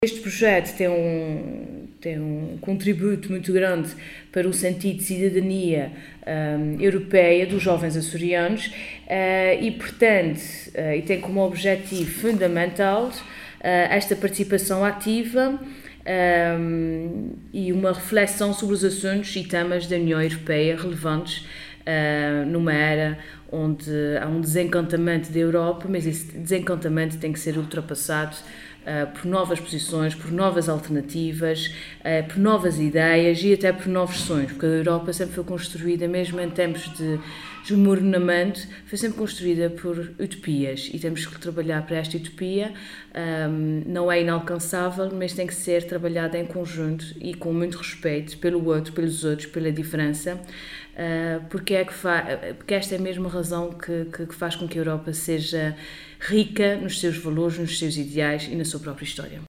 Pilar Damião de Medeiros, que falava à margem do encontro de jovens “Ser Europeu nos Açores”, que decorreu sábado em Ponta Delgada, considerou de “extrema relevância” a sensibilização dos jovens para a importância da sua participação cívica, “mostrando-lhes oportunidades de intervenção, através do contacto com decisores políticos regionais e europeus e, assim, criar um sentimento de partilha de conhecimentos, ideias, experiências e metodologias”.